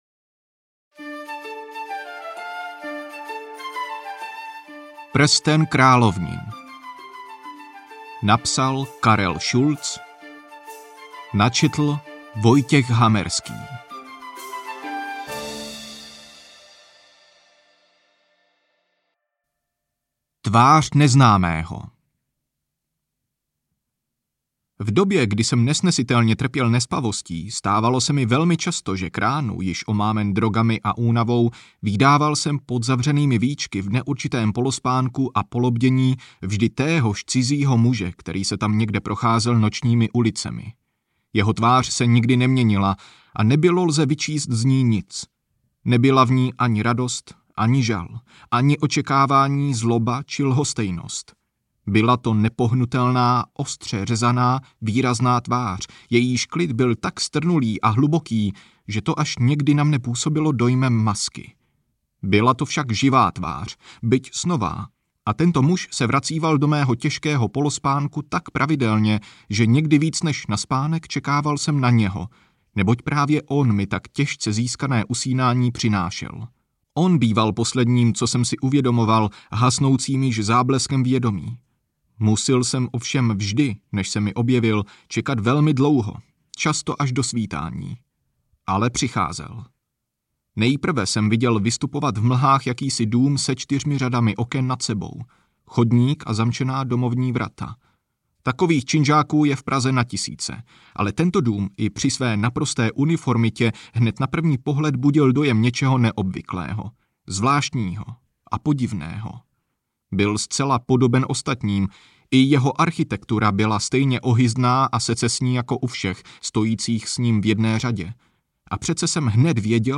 Prsten královnin audiokniha
Ukázka z knihy